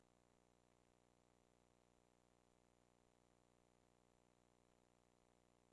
当没有输入信号时、它们只连接到3段适配器电缆、这会收集明显 的本底噪声。
我们强制将笔记本电脑的 GND 和直播台的 GND 连接在一起、这样噪声就可以降低一点。